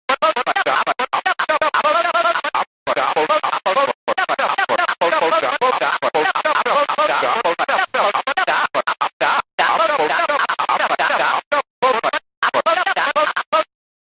Tenna Talking sound effect mp3 download
Tenna-Talking.mp3